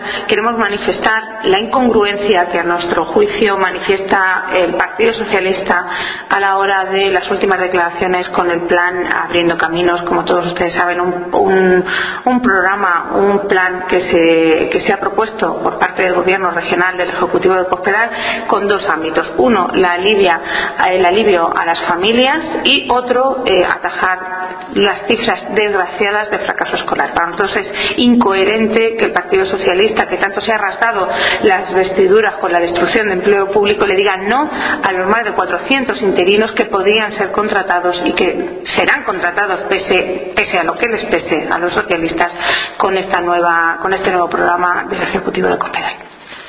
Cesárea Arnedo en rueda de prensa.